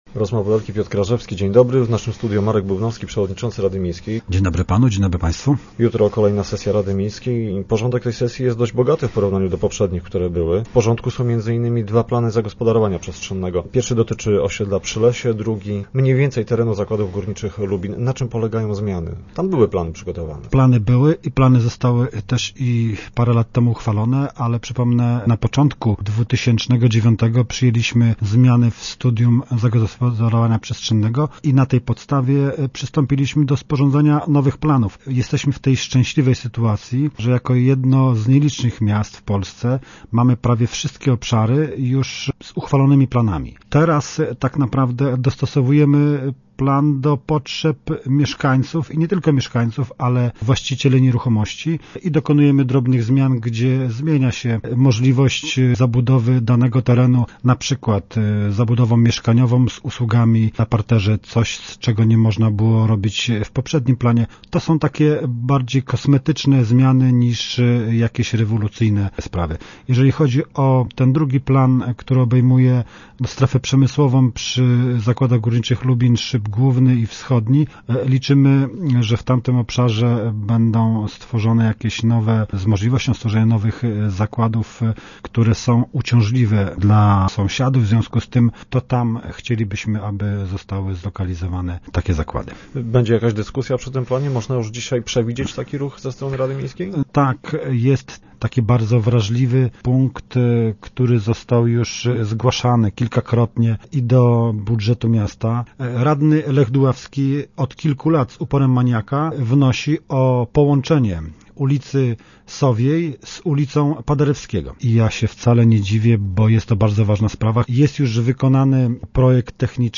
- Radny Lech Duławski od kilku lat wnosi o połączenie ul Sowiej z Piłsudskiego. To bardzo ważna sprawa i jest już wykonany projekt techniczny tego zadania. Radny zapowiedział, że będzie wnosił o zmianę do planu, aby ujęto na nim te drogowe połączenie - mówi Marek Bubnowski, przewodniczący Rady Miejskiej w Lubinie, gość Rozmów Elki.